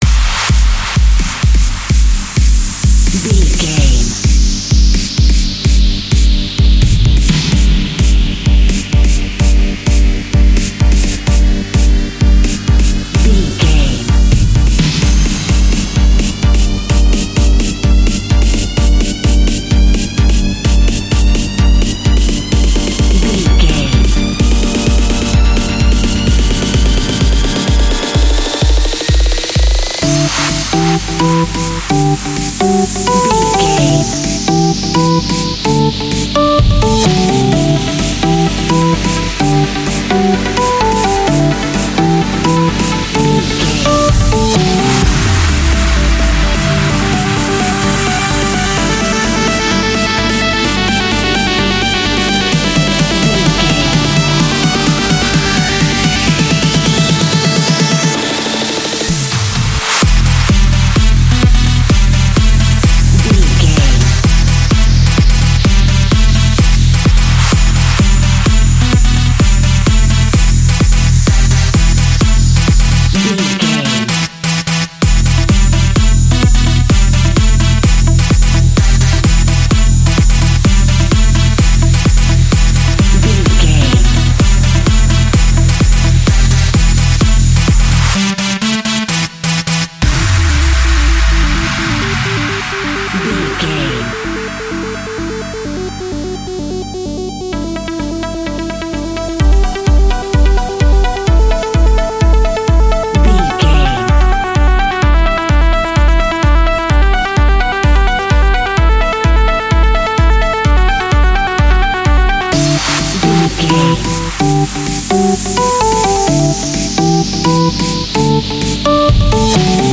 Trance Building.
In-crescendo
Ionian/Major
driving
uplifting
hypnotic
drum machine
synthesiser
acid house
electronic
synth leads
synth bass